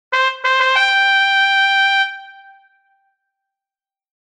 Trumpet Boom Boom Boom Sound Button - Free Download & Play